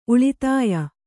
♪ uḷitāya